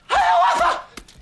Play, download and share ScaryMovie2AhhOhhAhah original sound button!!!!
scarymovie2ahhohhahah.mp3